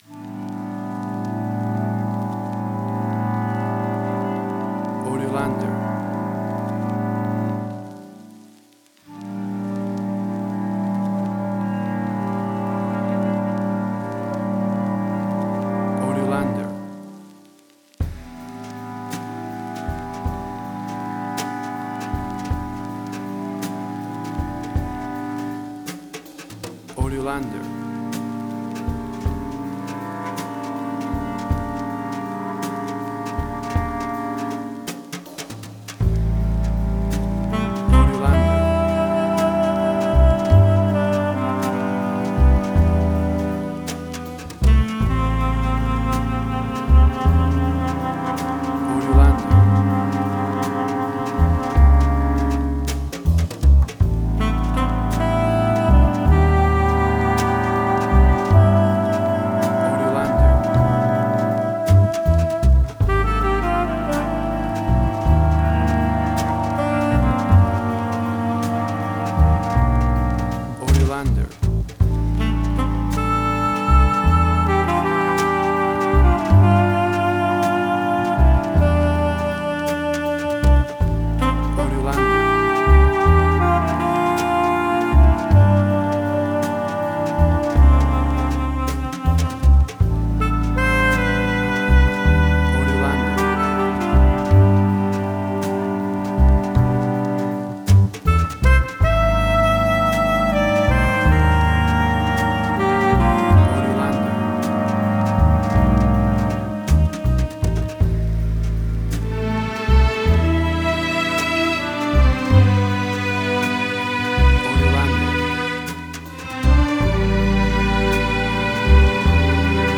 Modern Film Noir.
Tempo (BPM): 160